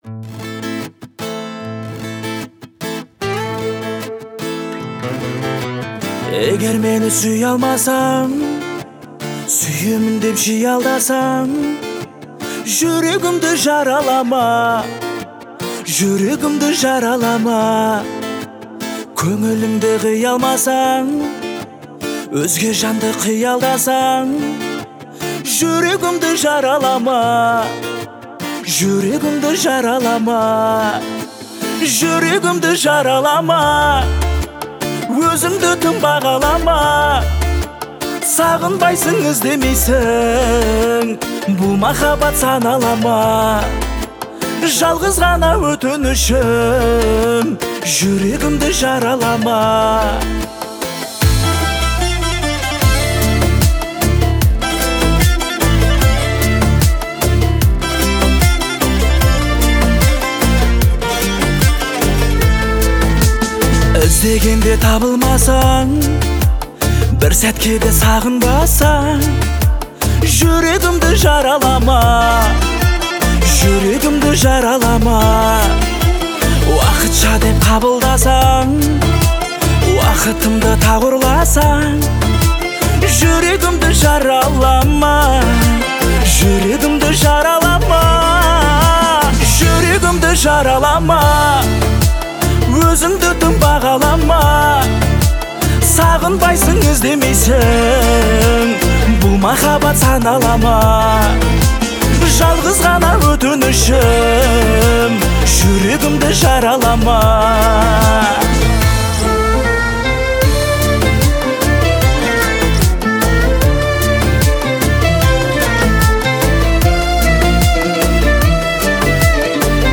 выполненная в жанре поп.